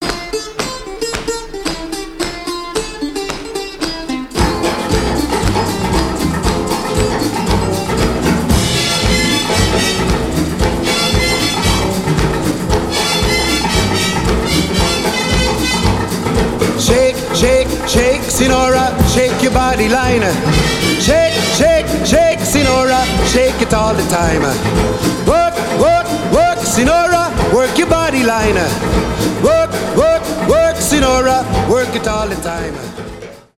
ретро , танцевальные , зажигательные , 60-е